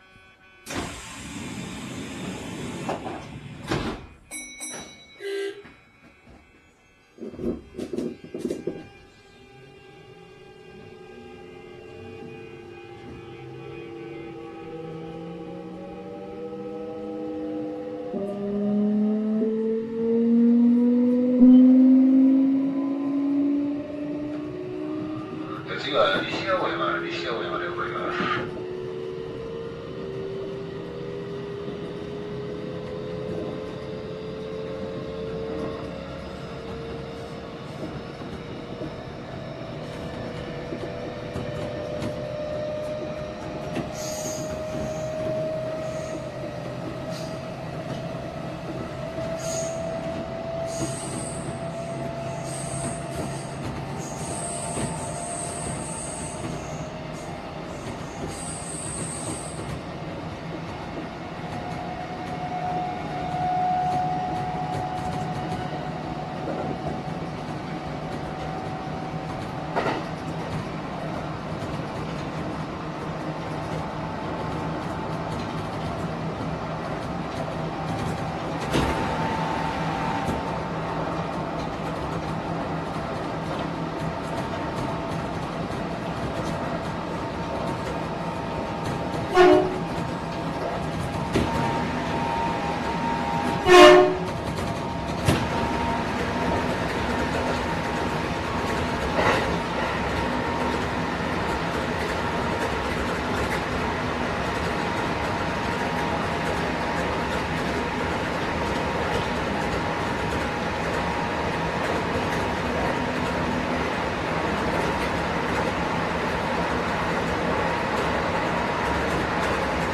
走行音（1255）
収録区間：大阪線 伊賀上津→西青山